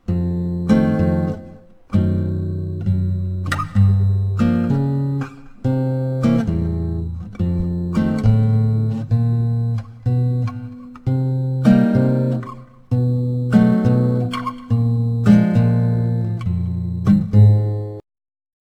version réduite et compressée pour le Web